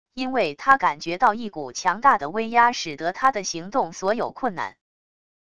因为他感觉到一股强大的威压使得他的行动所有困难wav音频生成系统WAV Audio Player